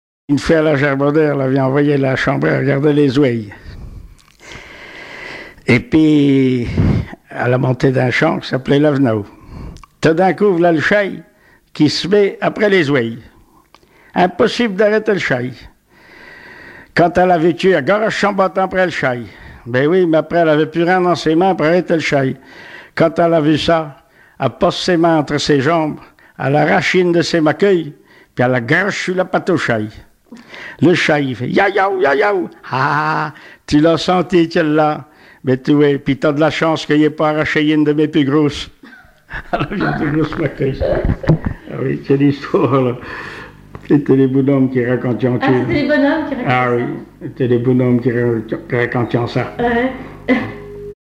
Genre sketch
Témoignages et chansons traditionnelles et populaires
Catégorie Récit